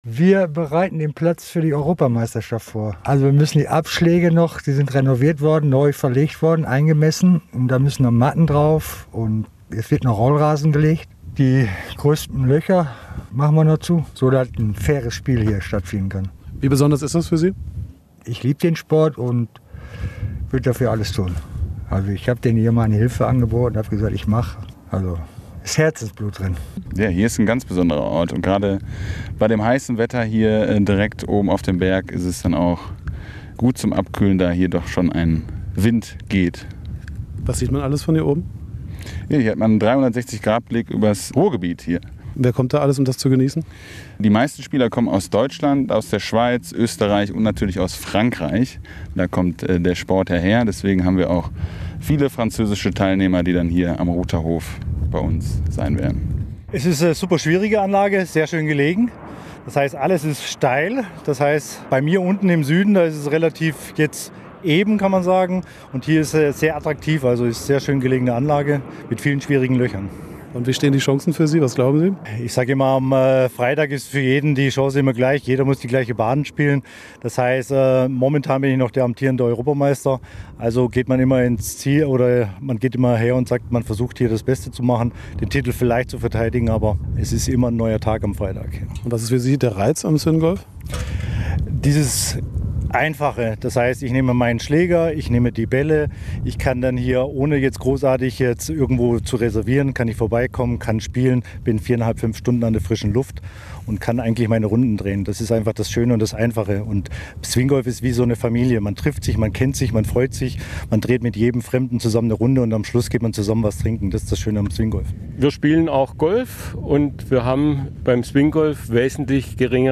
Wir haben mit Helfern und Spielern gesprochen.
Helfer und Spieler zur Europameisterschaft